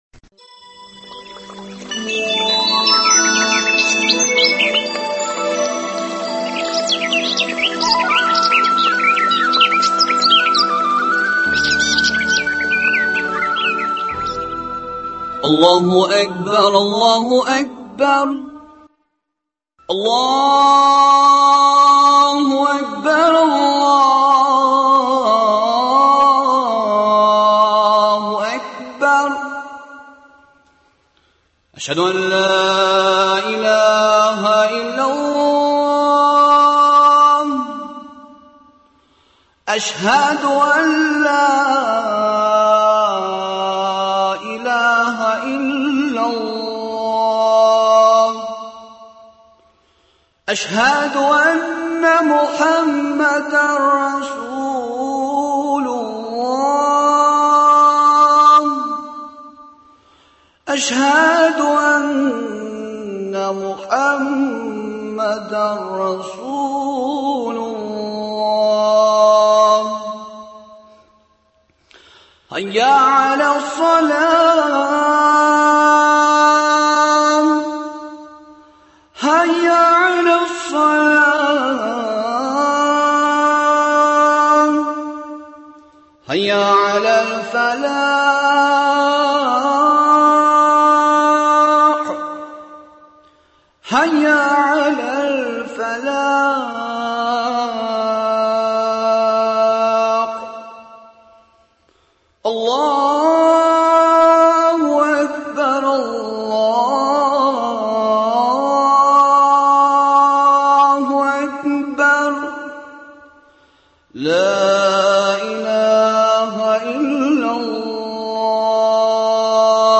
Бүгенге тапшырубызда да шушы ук темага Татарстанның баш казые Җәлил хәзрәт Фазлыев белән әңгәмә тәкъдим итәбез. Ул шулай ук тәфсилле итеп балалар тәрбиясе темасына да кагылачак.